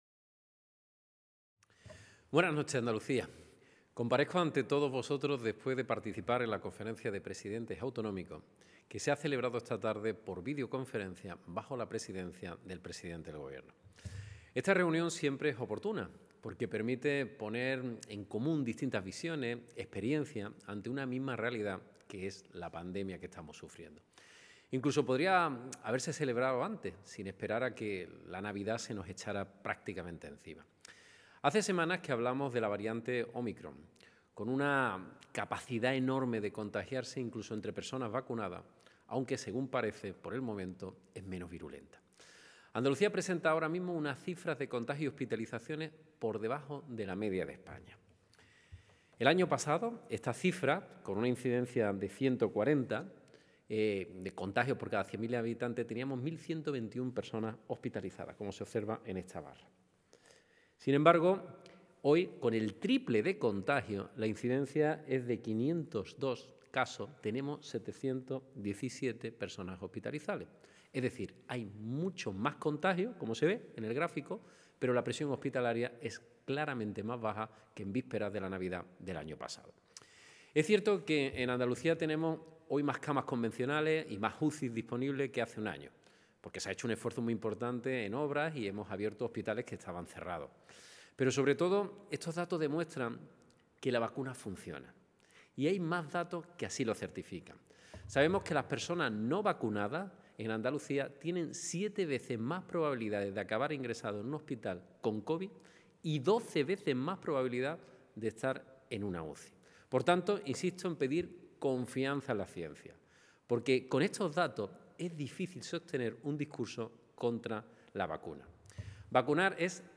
Moreno se ha pronunciado de este modo en una comparecencia pública desde el Palacio de San Telmo tras intervenir por vía telemática en la Conferencia de Presidentes convocada por Pedro Sánchez para abordar la situación de la pandemia del coronavirus en esta sexta ola.
El presidente de la Junta de Andalucía, Juanma Moreno